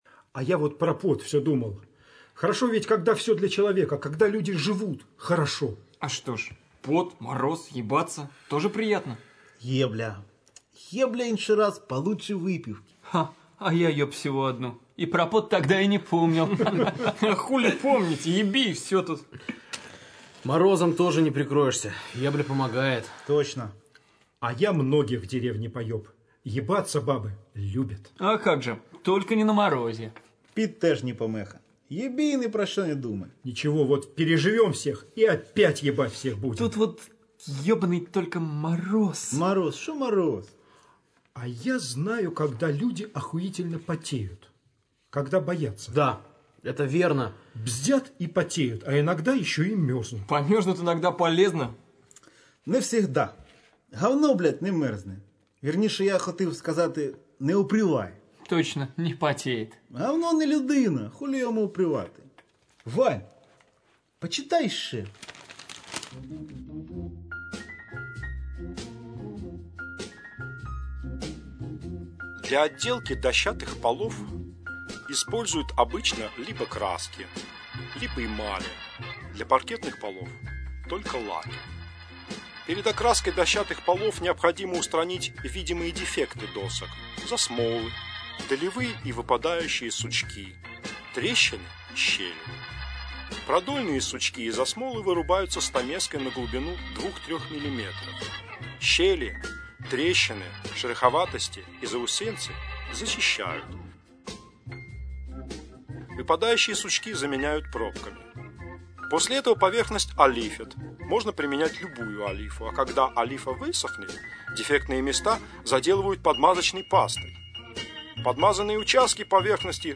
Радиопостановка пьесы Владимира Сорокина «Землянка», сделанная во время гастролей любительской театральной труппы журнала Game.EXE в кемеровском ДК «Металлург» в 2003 году.